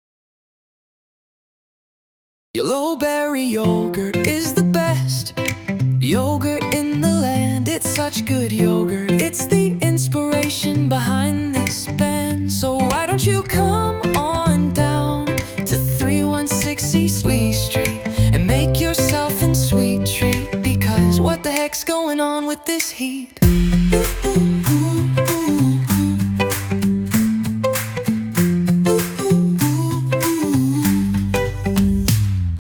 Pop Jingle